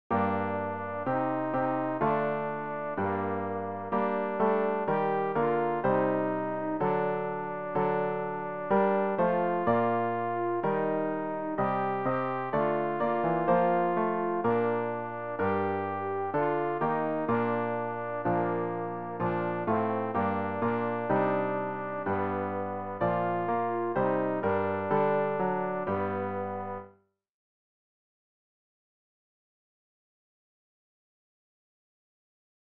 rg-448-du-gingst-o-heiland-hin-fuer-uns-zu-leiden-alt.mp3